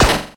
Download Bullet sound effect for free.
Bullet